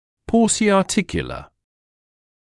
[ˌpɔːsɪɑː’tɪkjələ][ˌпоːcиаː’тикйэлэ]включающий в себя 2 или 4 сустава, при вовлечении 5 и более суставов используется polyarticular